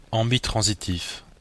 Ääntäminen
Belgique (Brabant wallon): IPA: /ɑ̃.bi.tʁɑ̃.zi.tif/